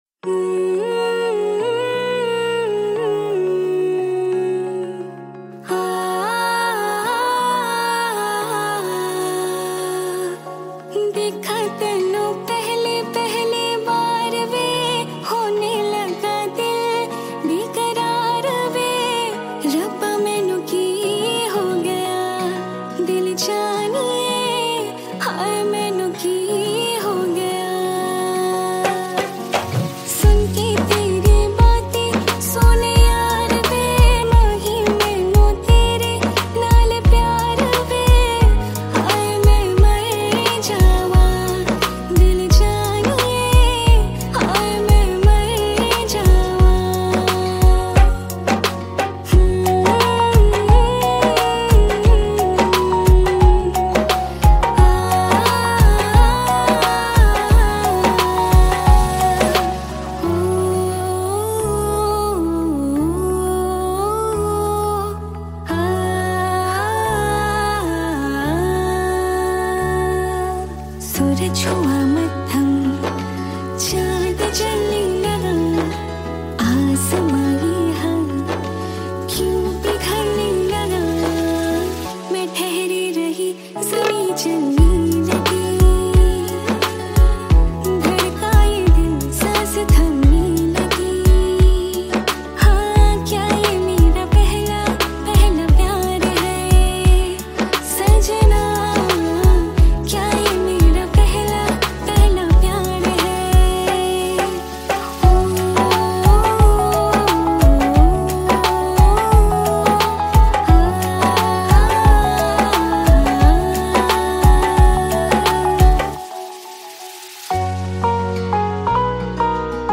Category New Cover Mp3 Songs 2022 Singer(s